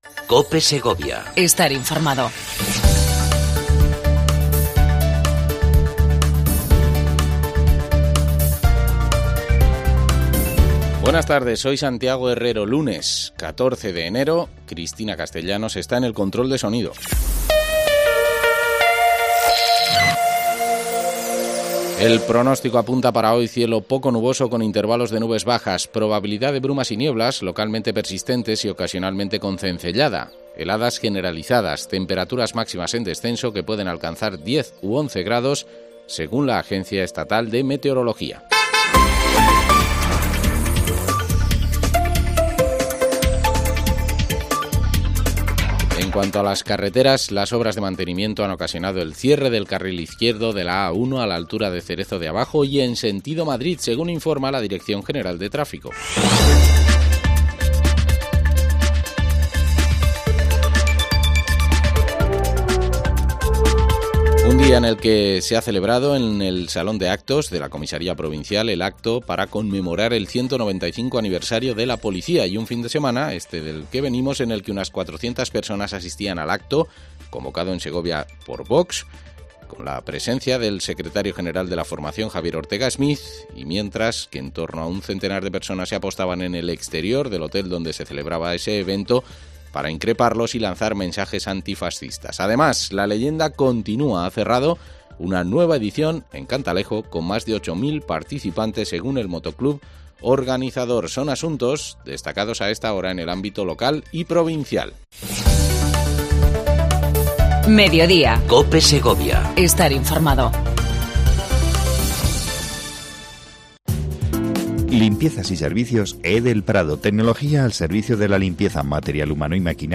AUDIO: Entrevista mensual a Clara Luquero, Alcaldesa de la capital segoviana con la que hablamos de la actualidad de la provincia.